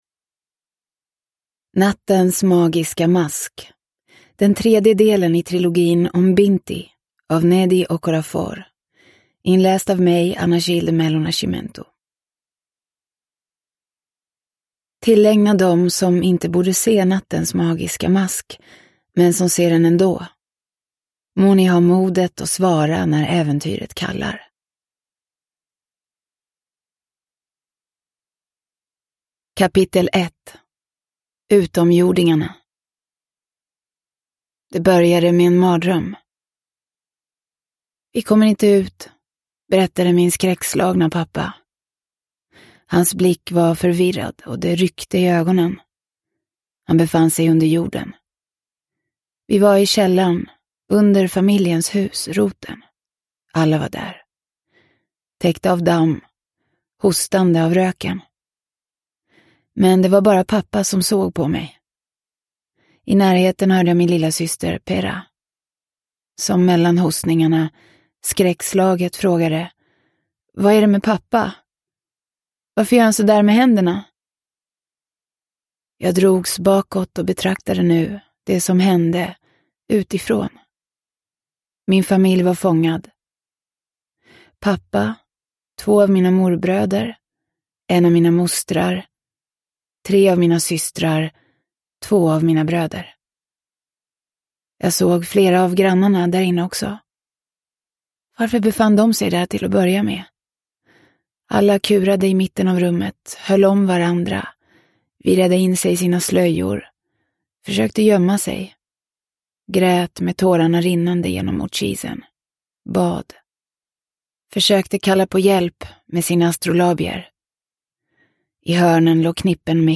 Binti 3: Nattens magiska mask – Ljudbok – Laddas ner